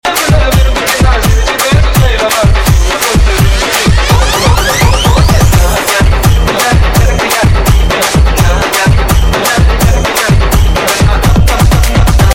dj remix song